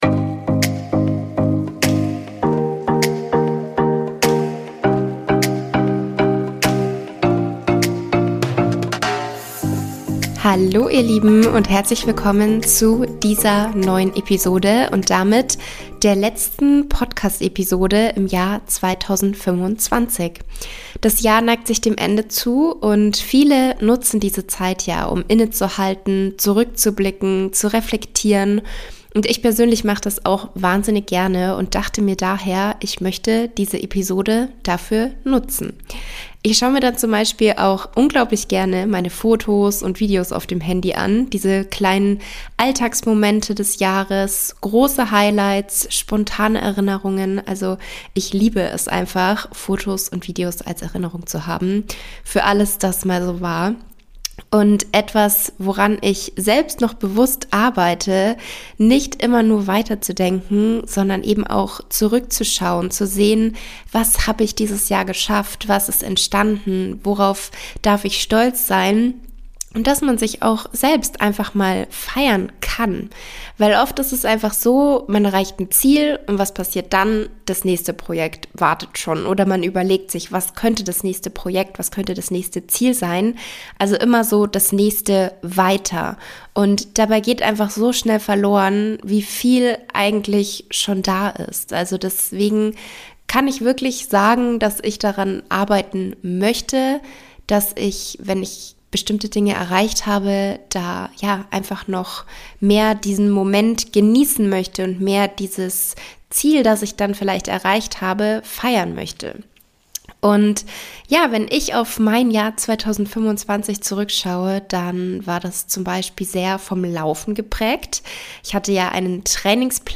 Diese Episode ist ein Rückblick auf das Podcastjahr 2025 – ein Mix aus vielen Gesprächen, Momenten und Stimmen, die dieses Jahr geprägt haben. Du hörst Ausschnitte aus unterschiedlichen Episoden sowie Stimmen aus dem Circle of Balance, die ihre persönlichen Erfahrungen rund um Gesundheit, Routinen und Balance teilen. Thematisch geht es um all das, was uns hier immer wieder begleitet: Bewegung und Training, Ernährung, Stressmanagement, Achtsamkeit, Frauengesundheit, Mindset und die Frage, wie ein gesunder Lebensstil aussehen kann, der sich an dein Leben anpasst – ohne Perfektion, ohne Druck.